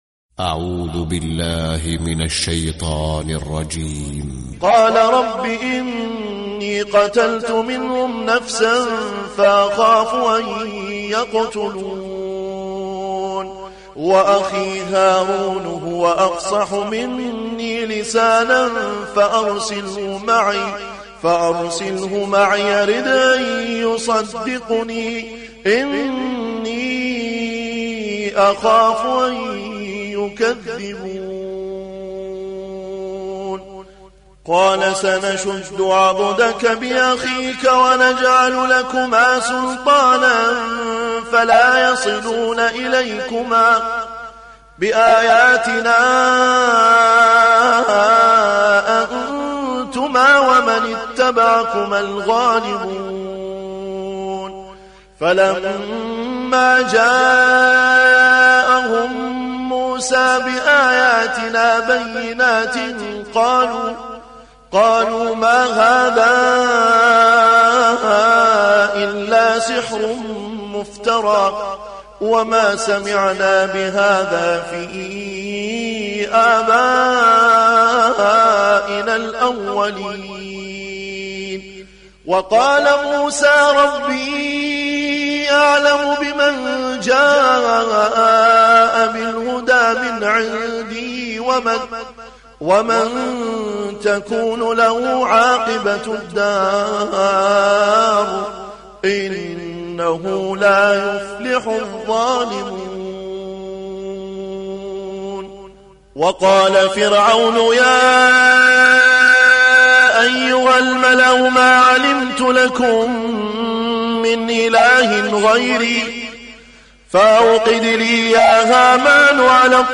🌾💛•تلاوة مميزة•💛🌾